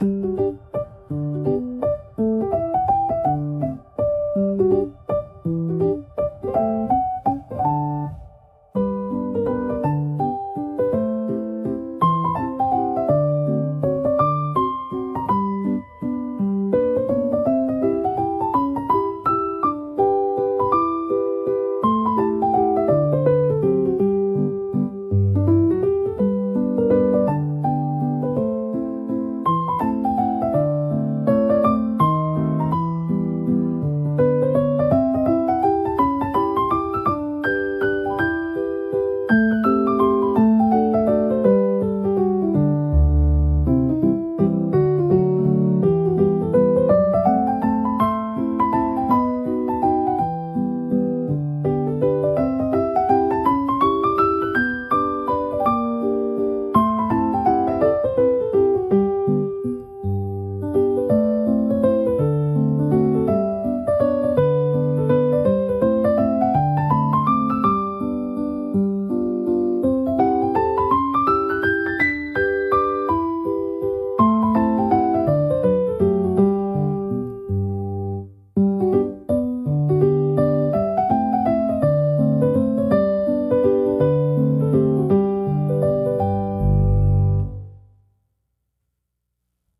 シンプルなメロディラインが心地よいリズムを生み、穏やかながらも前向きなムードを演出します。
ピアノの柔らかなタッチが集中をサポートし、疲れを癒す効果を発揮します。